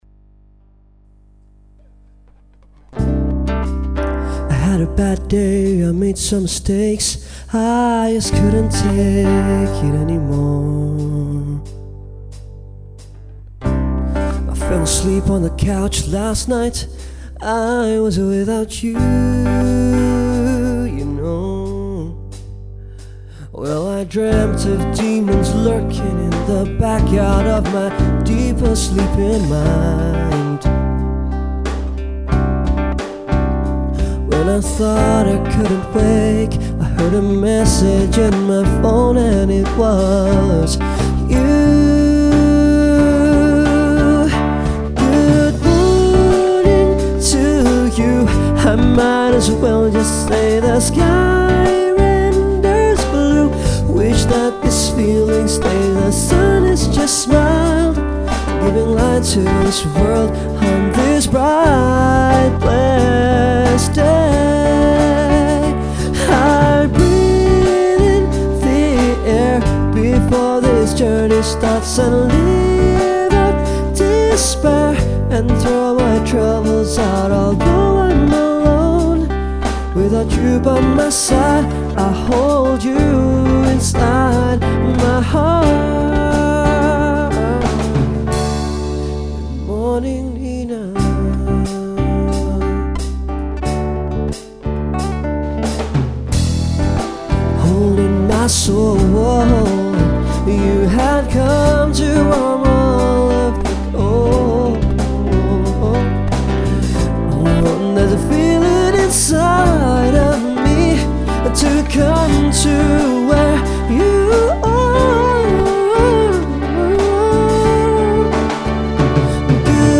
re-recorded 2010
recorded at Dubai Marina